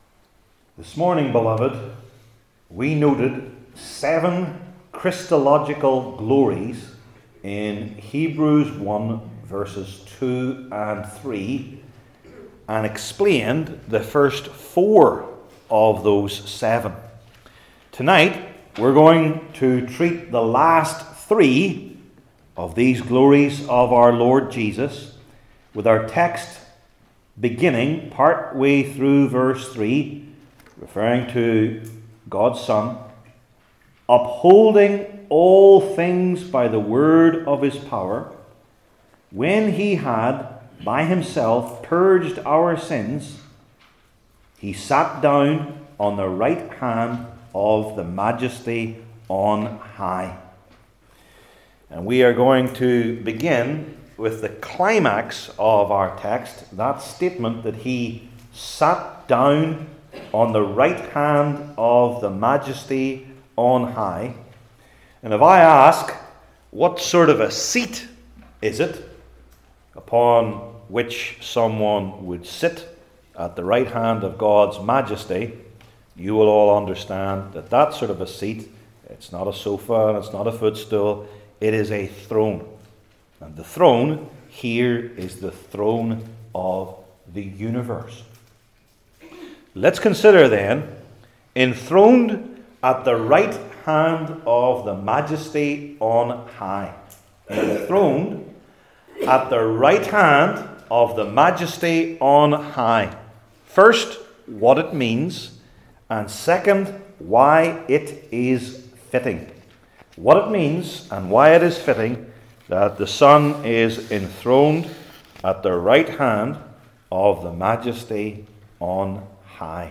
New Testament Sermon